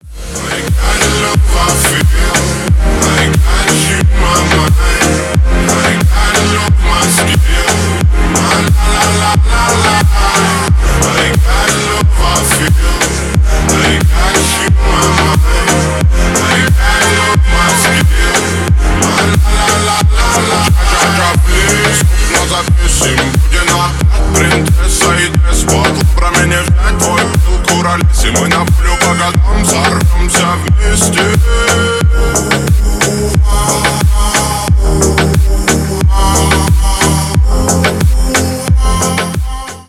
Ремикс # Танцевальные
громкие